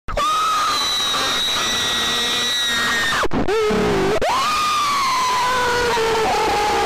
Loud Funny Scream